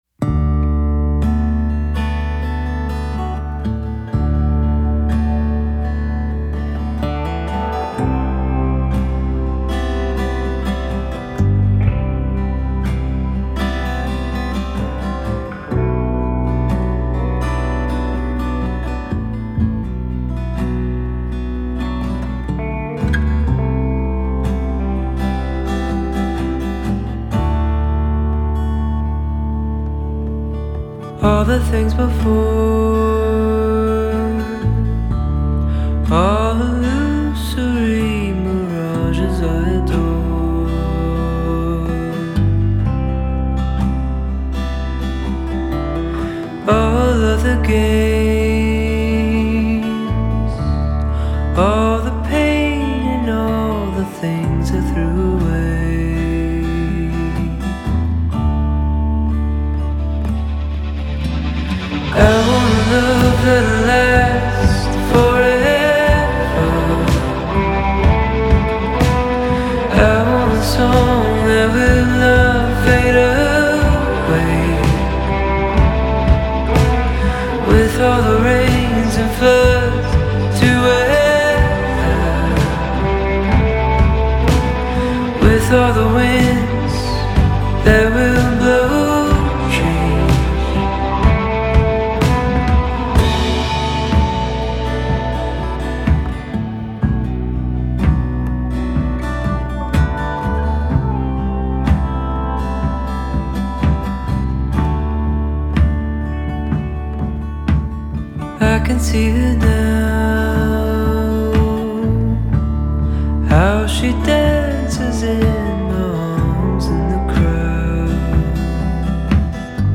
Acoustic guitar
Electric guitars
Synthesizers and keys
Bass
Drums
Background vocals
Horns
Pedal steel